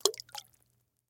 splash.mp3